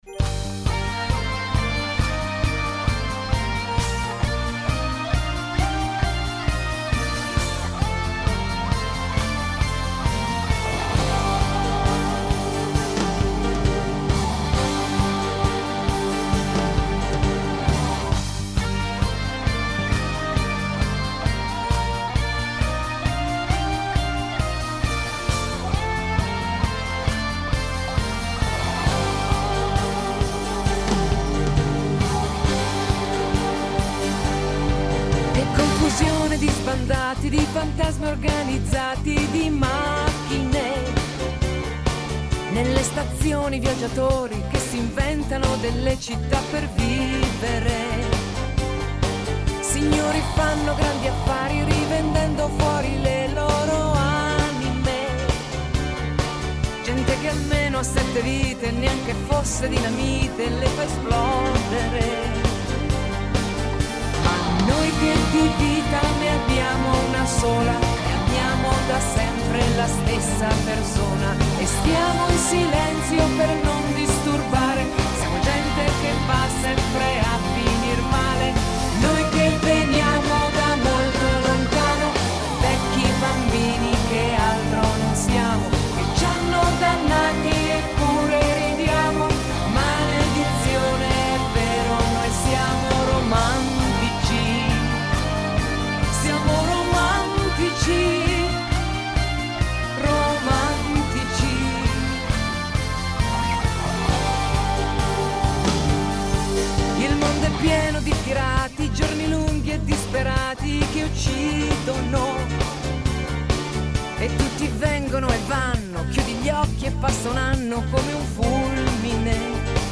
Incontro con Viola Valentino, cantante e attrice italiana